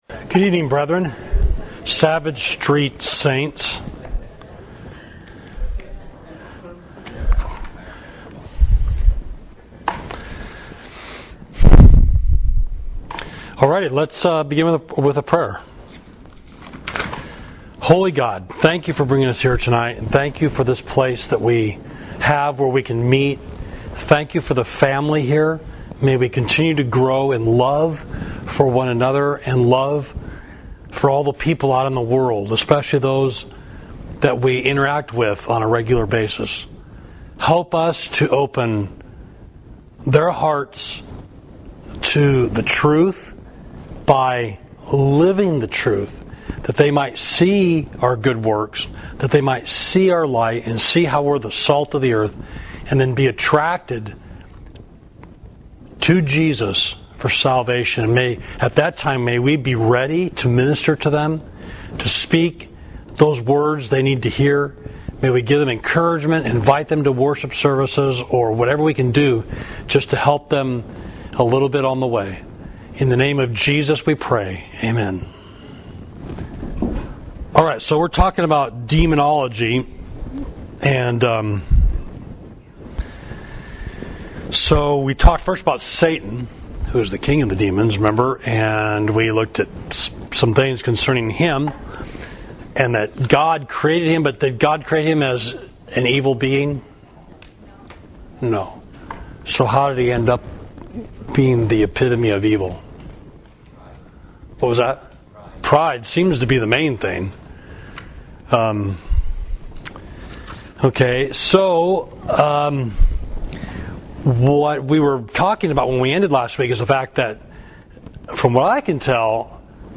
Class: Demonology